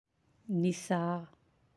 (nisaa’)